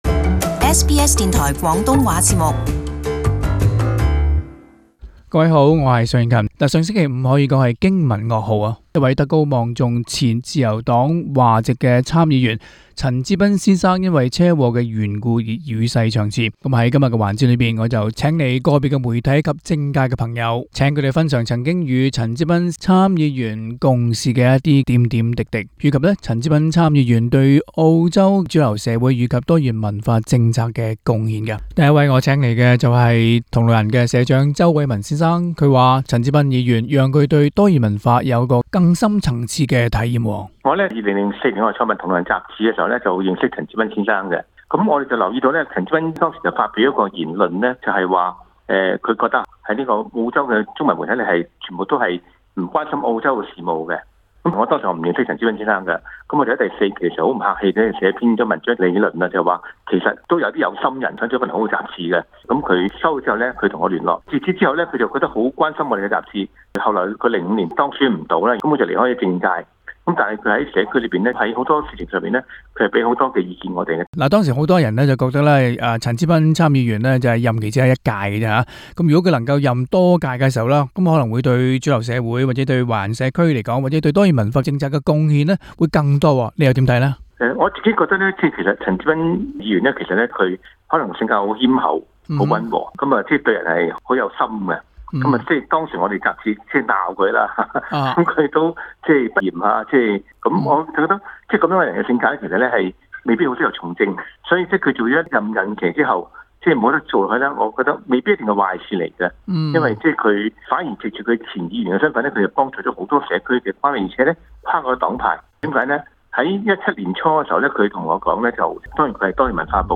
【社區專訪】環節報導澳洲首位華裔聯邦參議員陳之彬車禍，傷重不治。